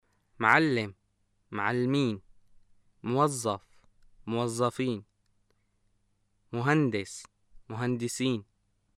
[mʕallem (mʕallmiin), mwaZZaf (mwaZZafiin), mhandes (mhandsiin)]